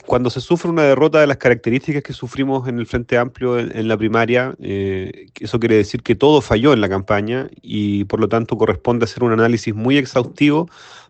El diputado y jefe de bancada del Frente Amplio, Jaime Sáez, planteó que es necesaria una autocrítica para no repetir los mismos errores en futuros procesos electorales.